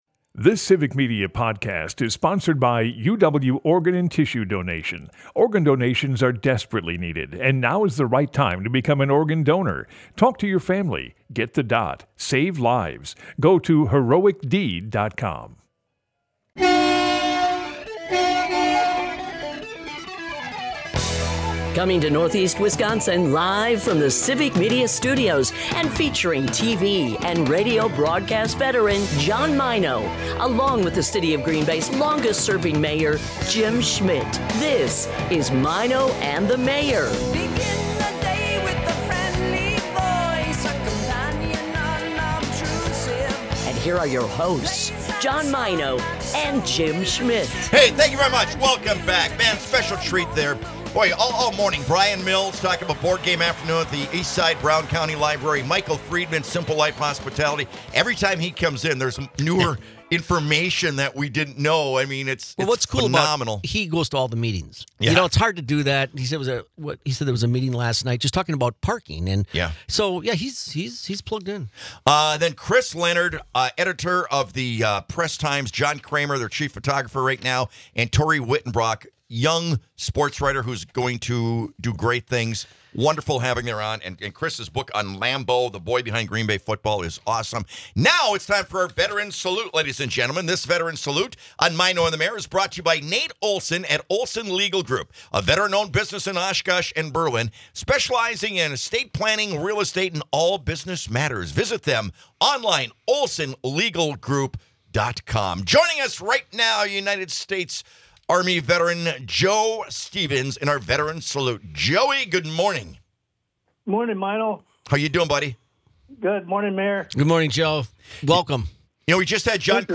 The show wraps up with the guys discussing university and college and their time in higher education. Dive bars, Indian food, and yodeling are also subjects of conversation.